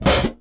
Medicated OHat 7.wav